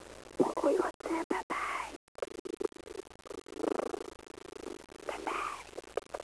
Celui-ci est encore moi qui ronronne pour dire Bye bye!